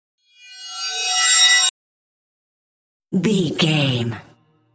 Synth Swell Mid | VGAME
Middle Synth Swell.
Sound Effects
In-crescendo
Atonal
ominous
eerie
synthesizer